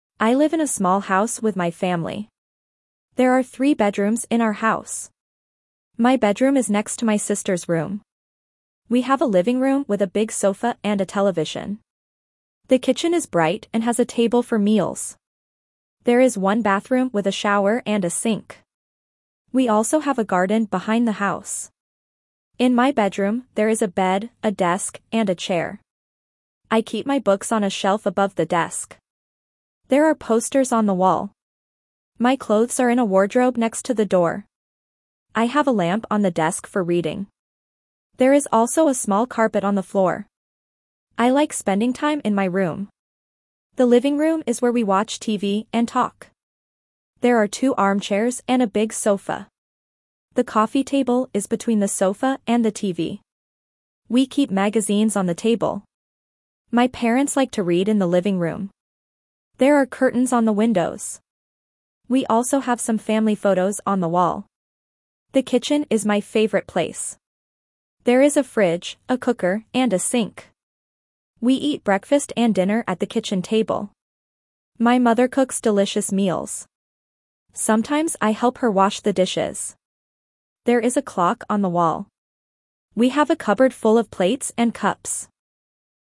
Reading A1 - My House